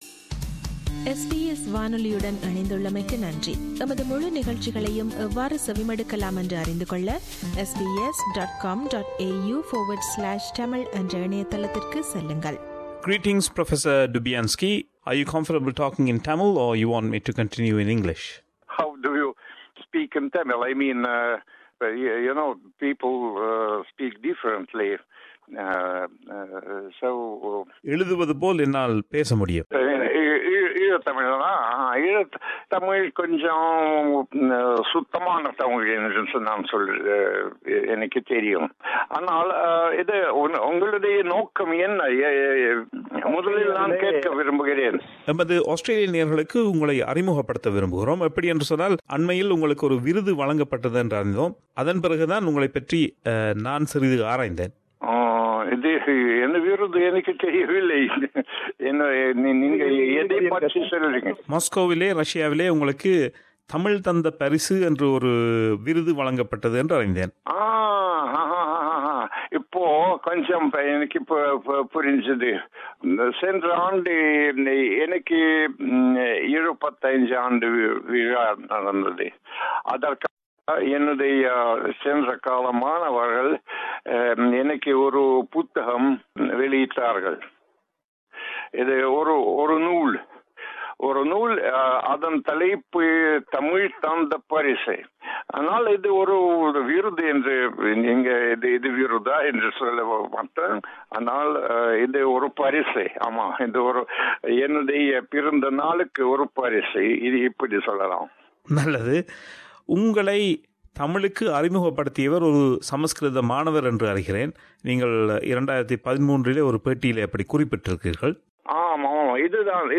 அவர் சில ஆண்டுகளுக்கு முன்னர் நமக்கு வழங்கிய நேர்காணல் இது.